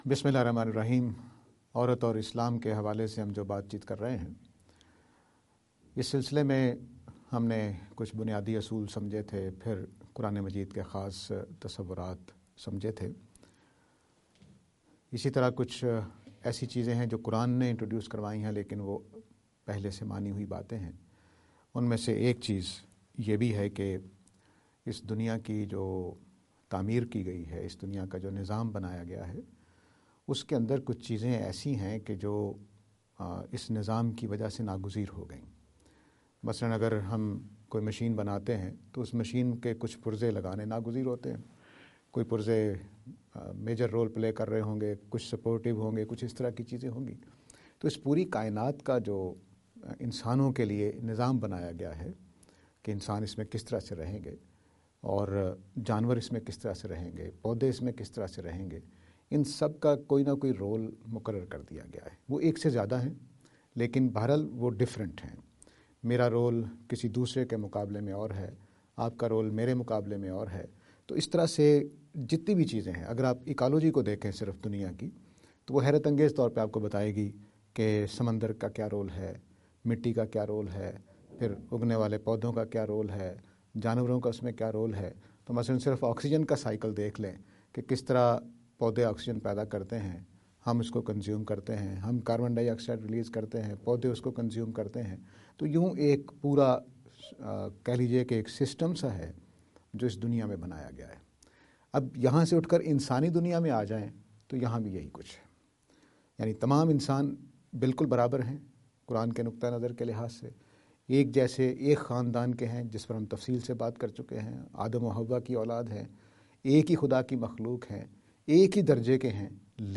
lecture series on "Women and Islam".